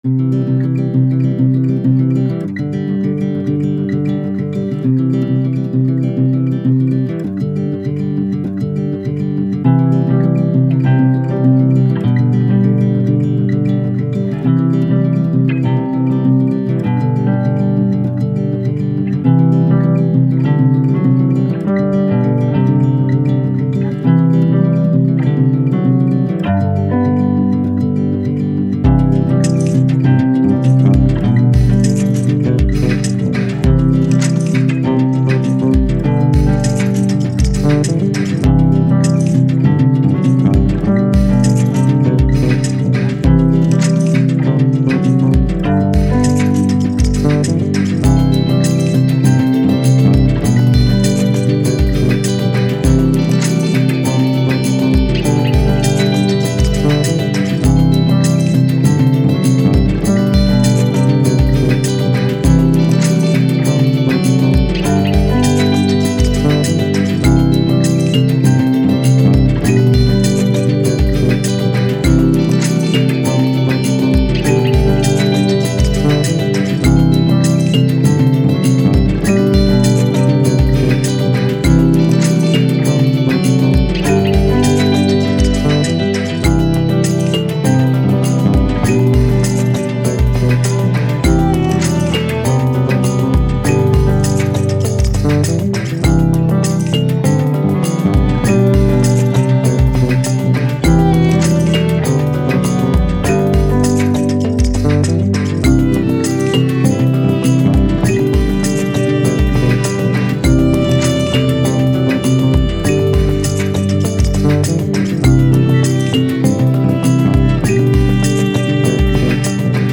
Ambient, Downtempo, Soundtrack, Hopeful